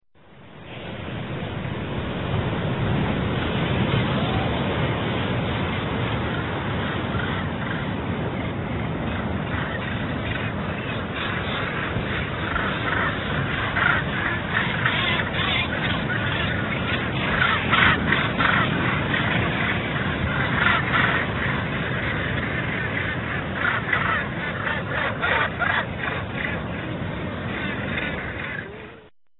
ocean.ra